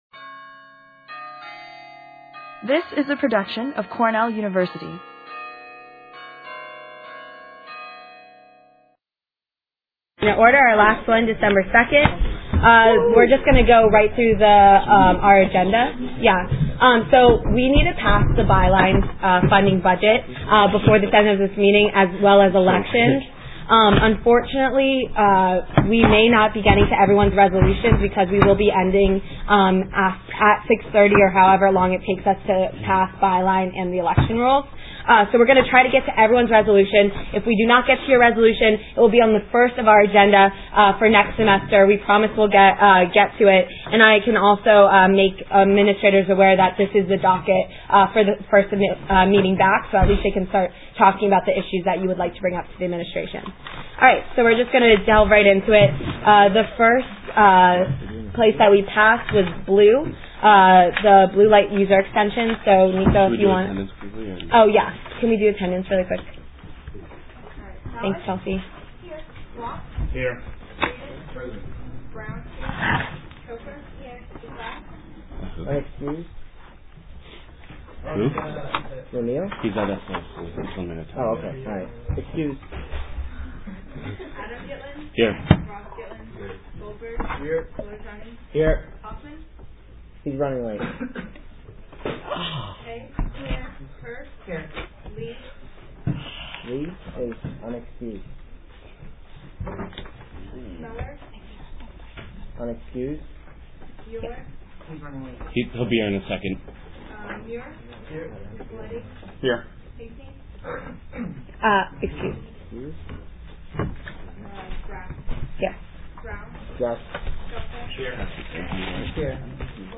Due to technical difficulties, only the first 23 minutes of the meeting were able to be recorded